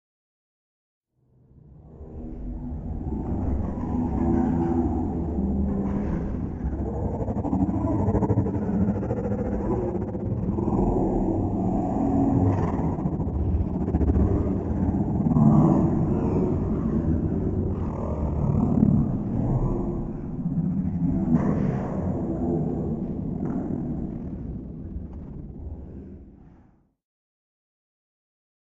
Alien Talking 1 - Big Reverb Long